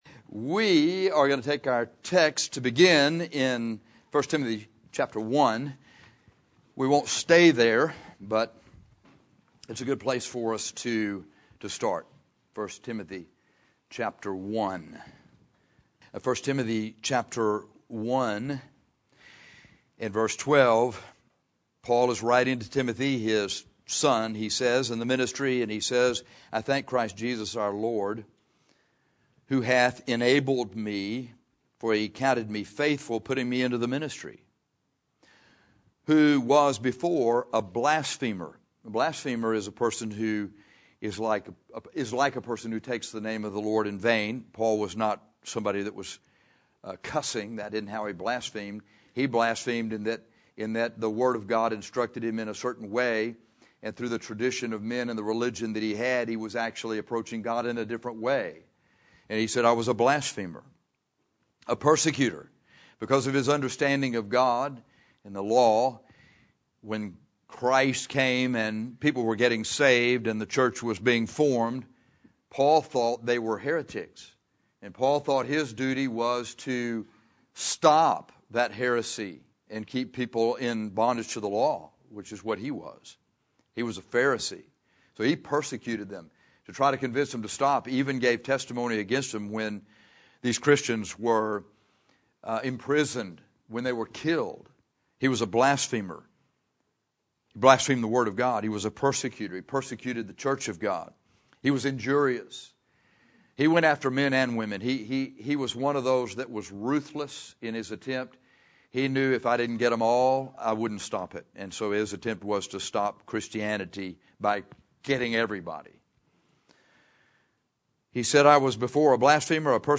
What's Holding You Back? 1 Tim 1:12-13 - Bible Believers Baptist Church